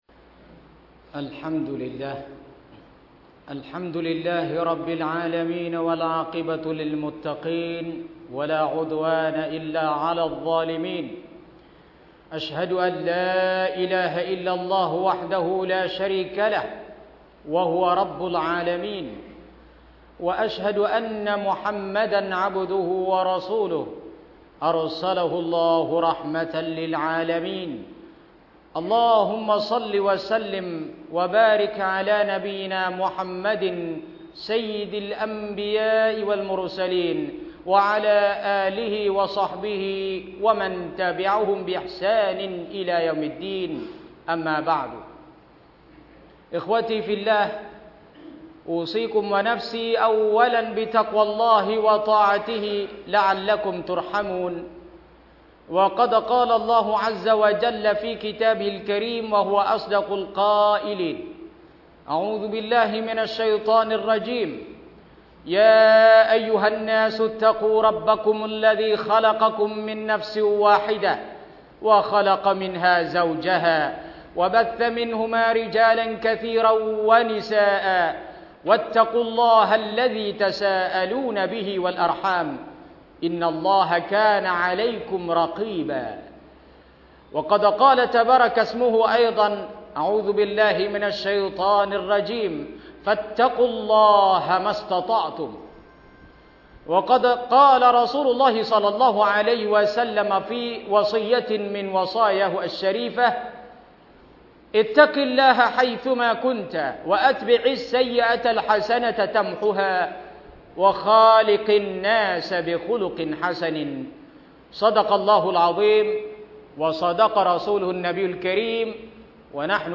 มัสยิดกลางจังหวัดชลบุรี ดาวน์โหลดไฟล์เสียง
คุตบะฮฺวันศุกร์ : ธาตุแท้ของมนุษย์